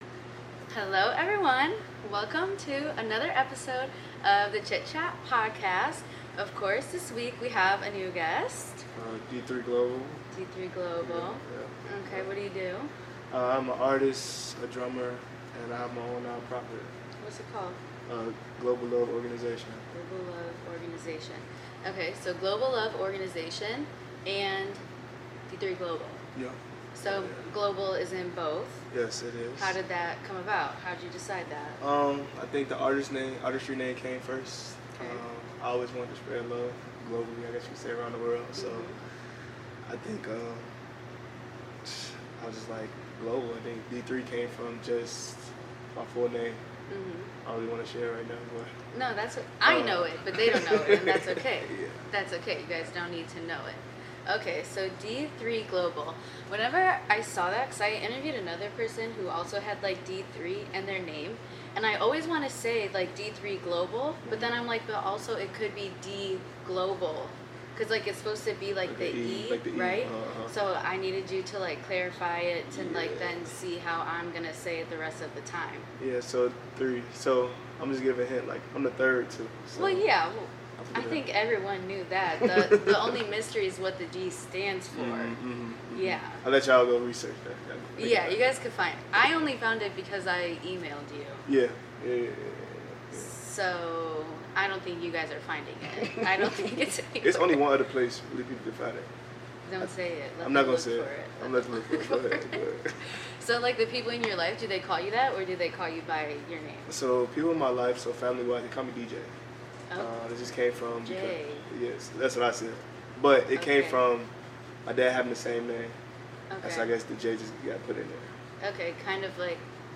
Be a guest on this podcast Language: en Genres: Arts , Music , Music Interviews , Performing Arts Contact email: Get it Feed URL: Get it iTunes ID: Get it Get all podcast data Listen Now...